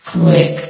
S.P.L.U.R.T-Station-13/sound/vox_fem/quick.ogg
* New & Fixed AI VOX Sound Files